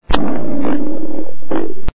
Body Sound Effects
The sound bytes heard on this page have quirks and are low quality.
BURP # 3 ( A BARATONE BURP ) 1.84